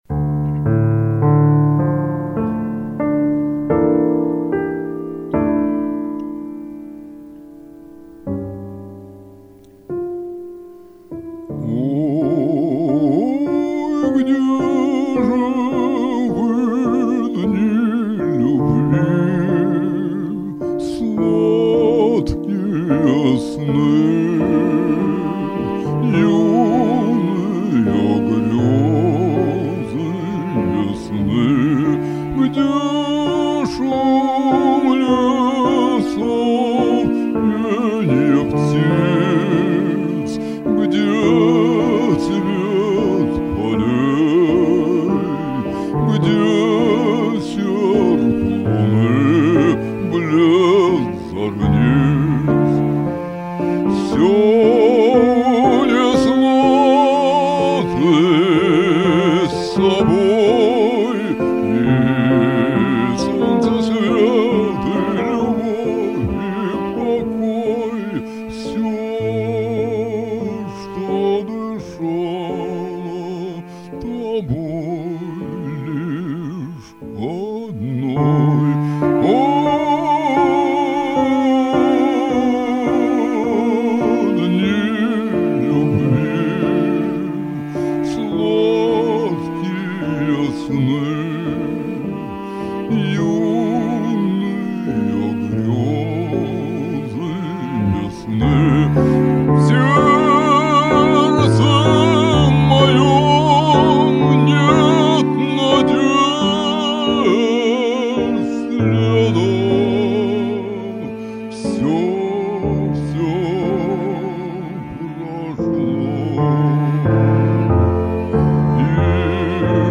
Оба прочтения хороши и прозвучали достойно.